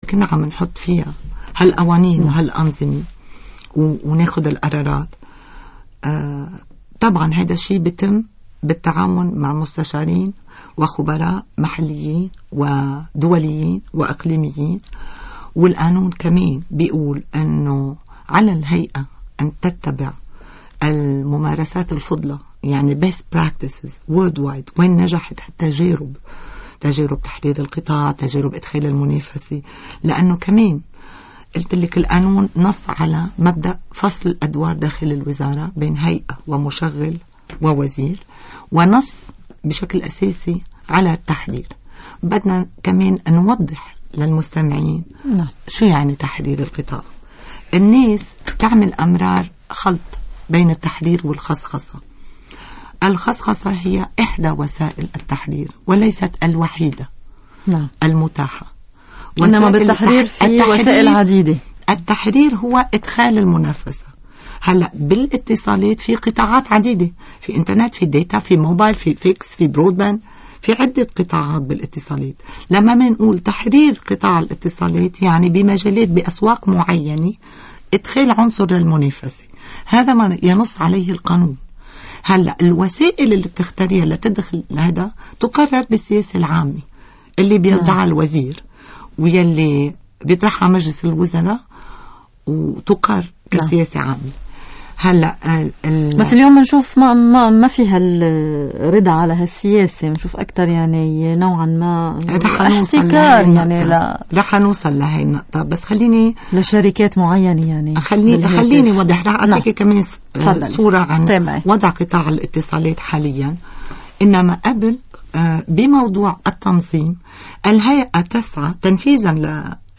Mrs. Mahassen Ajam, Commissioner, Board Member, and Head of Information and Consumer Affairs Unit at the TRA, was interviewed